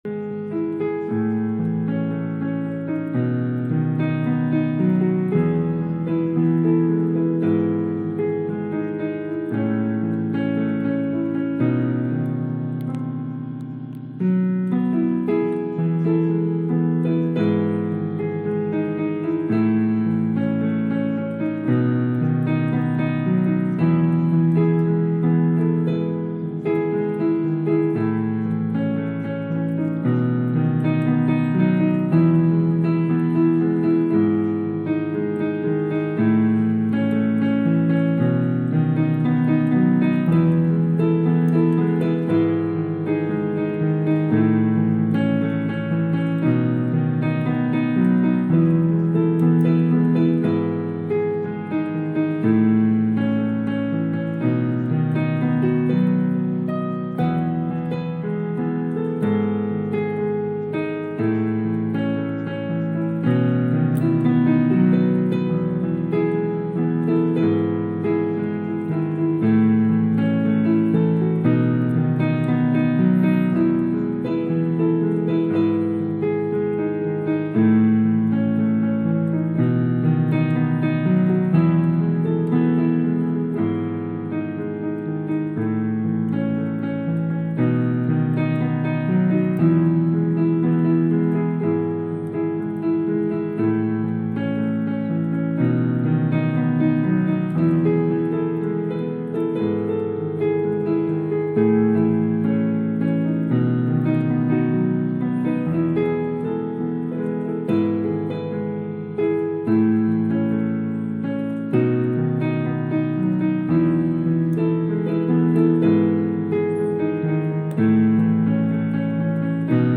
新季节 HAKA祷告 第17天 呼召（三）何西阿蒙召
启示性祷告：